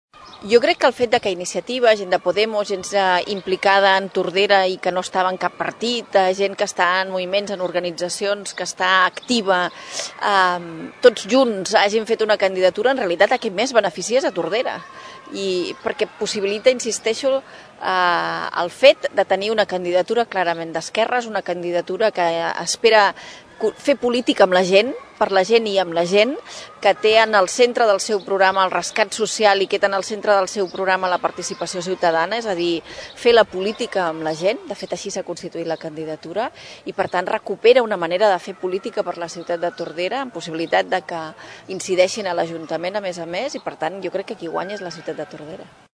Per la seva banda, Dolors Camats va destacar que amb Som Tordera el poble guanya perquè és una candidatura amb “polítiques fetes per la gent i amb la gent”.